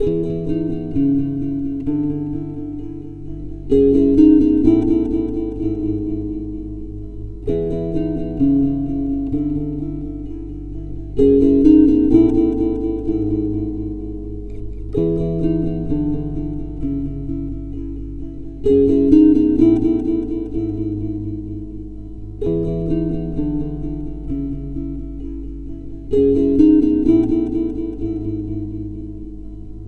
Recorded Bday 2010, Clips made August in Calais
Guitar_01_1_8bars.wav